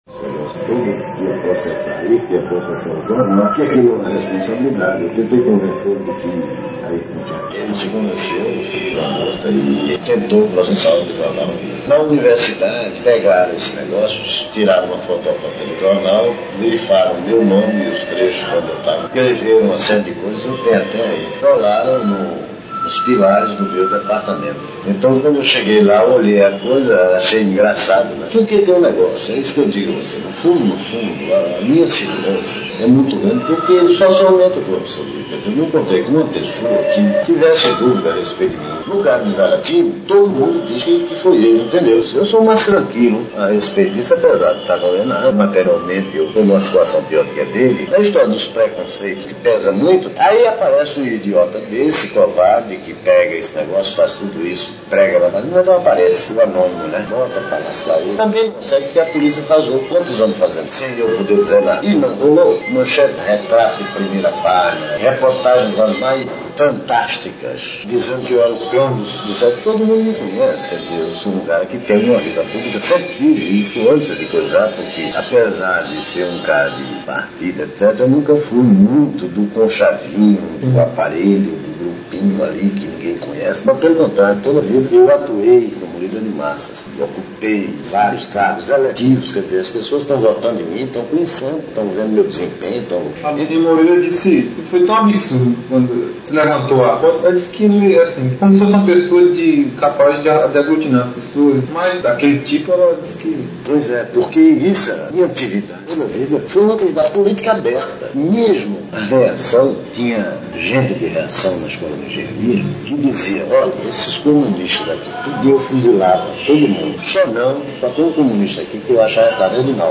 Discurso do deputado Francisco Julião
Discurso do deputado Francisco Julião Discurso do deputado Francisco Julião em 31 de março de 1964 na Câmara dos Deputados.